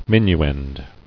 [min·u·end]